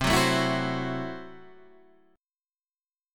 C6b5 chord